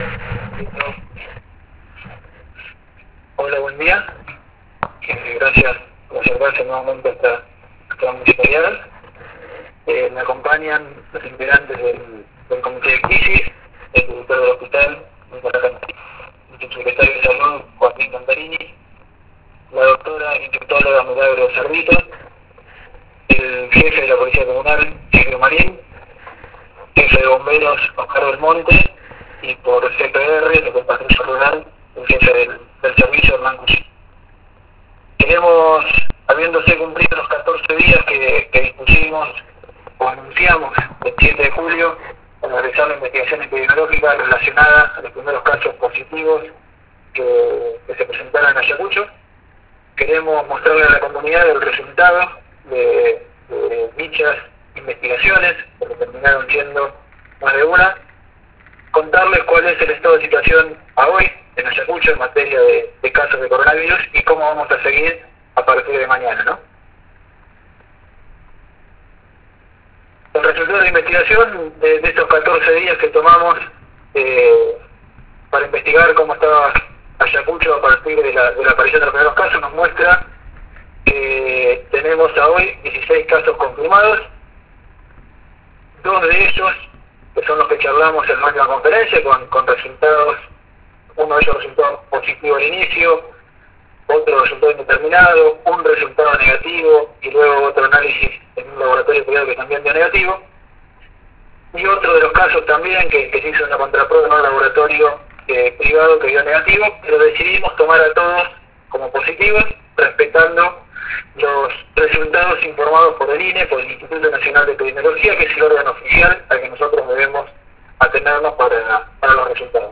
Con la presencia de los referentes del comité de crisis integrado por Bomberos, CPR y Policía Comunal, se hicieron anuncios sobre cambios en el funcionamiento de la ciudad;